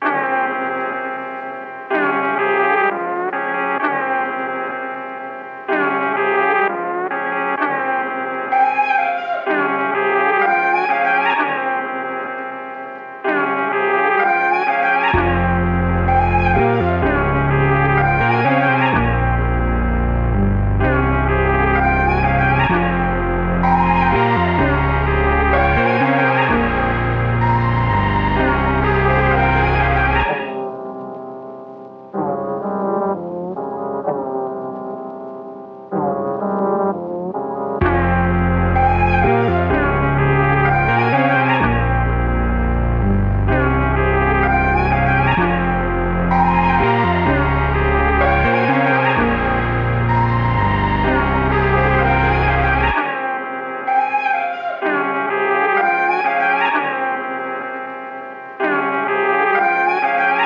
[MAMA] - SAMPLE - 127BPM.wav